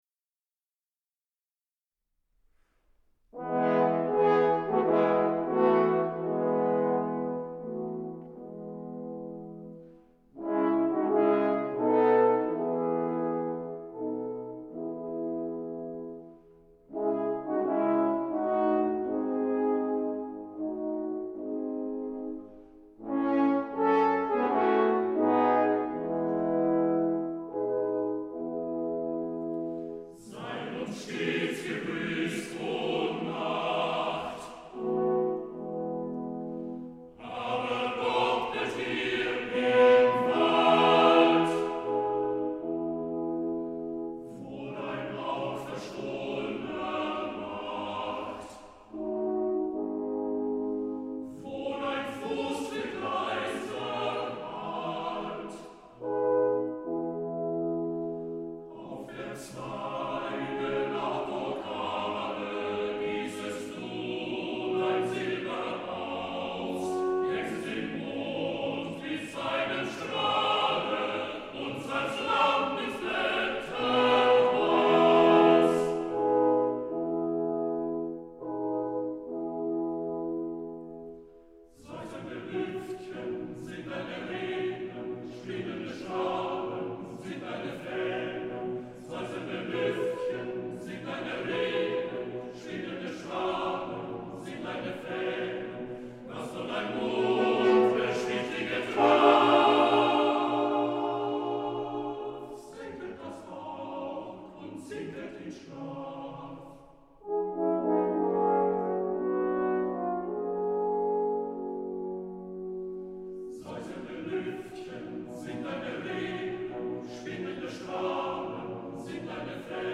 Gesänge für Männerstimmen und Klavier
Horn